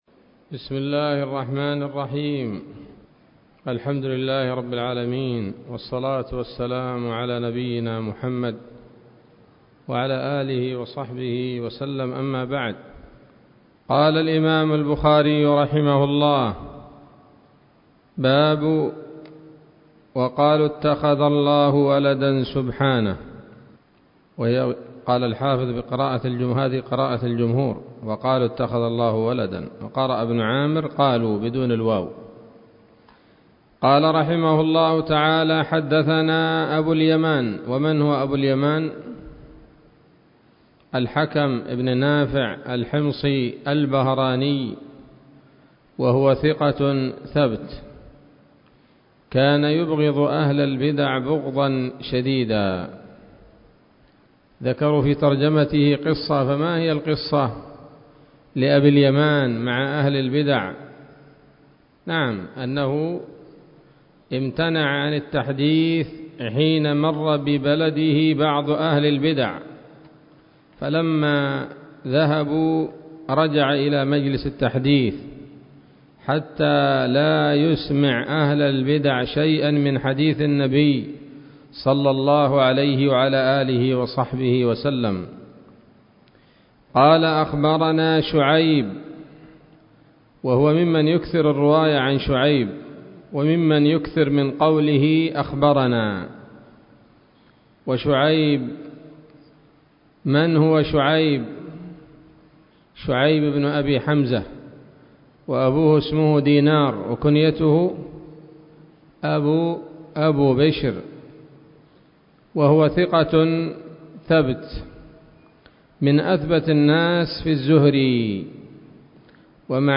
الدرس التاسع من كتاب التفسير من صحيح الإمام البخاري